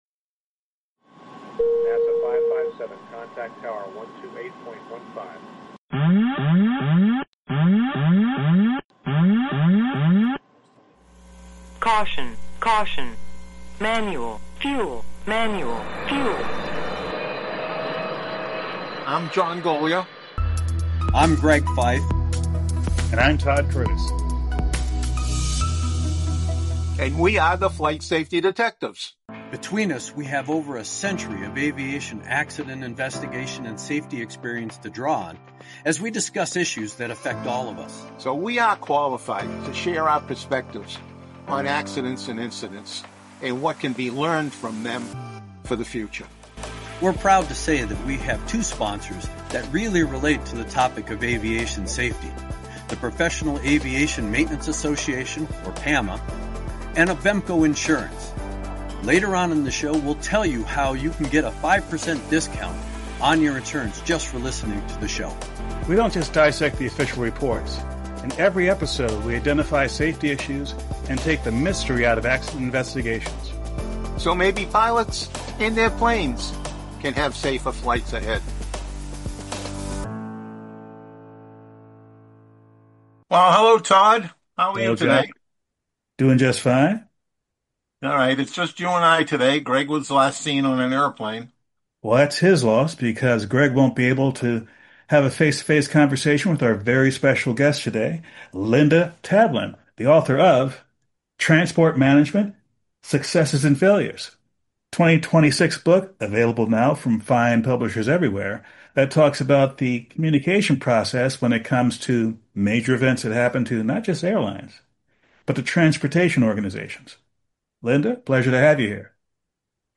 1 More Than Just Flowers | Interview